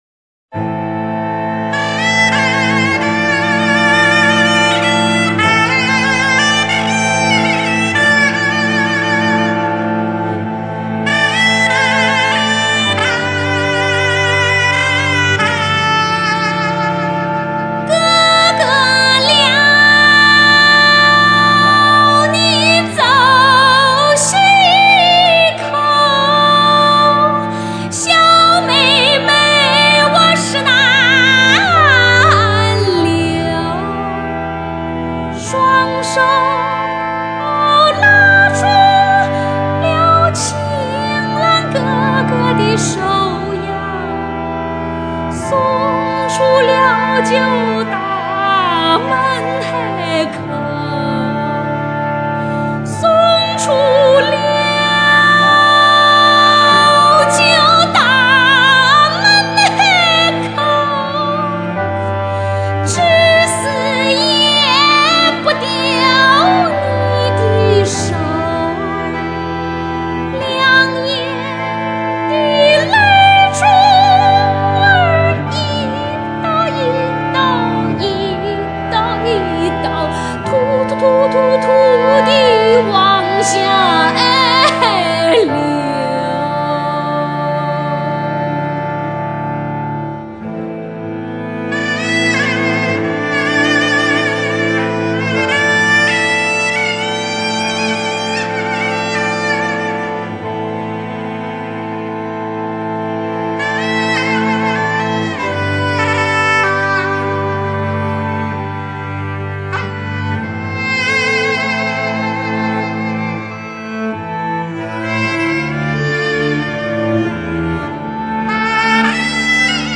陕北民歌